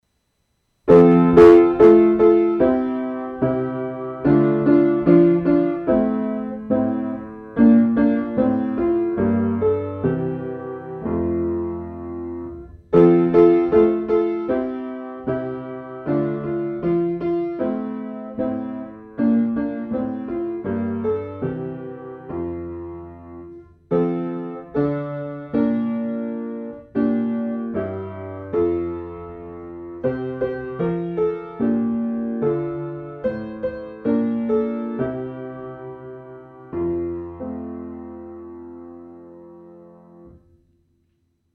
Egyházi ének hanganyag